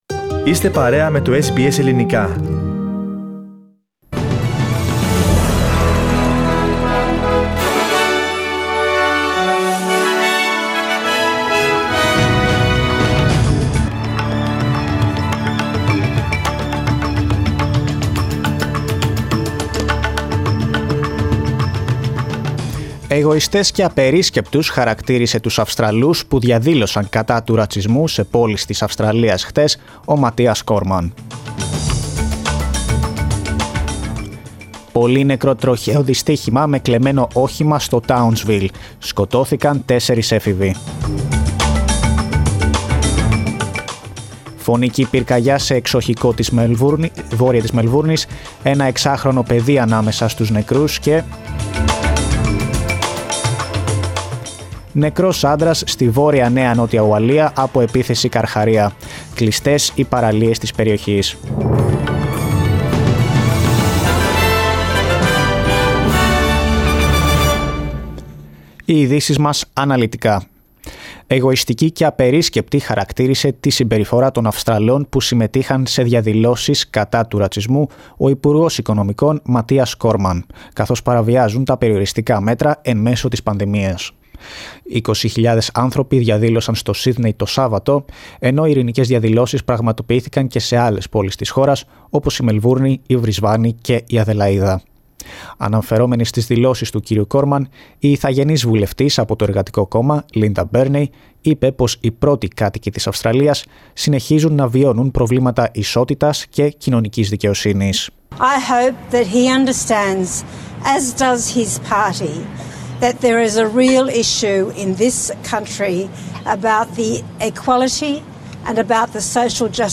News from Australia, Greece, Cyprus and the world in the News Bulletin of Sunday 7th of June.